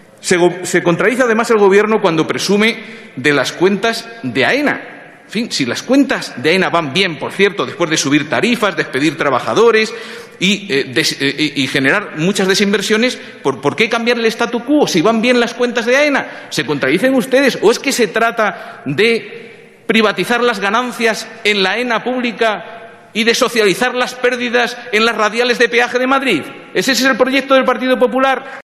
Fragmento de la intervención de Rafael Simancas en el debate sobre la proposición no de ley de Del Grupo Parlamentario Vasco (EAJ–PNV), relativa a la privatización de AENA. 16/09/14